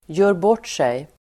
Ladda ner uttalet
Uttal: [jö:rb'årt:sej]